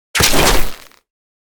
break_wolf.ogg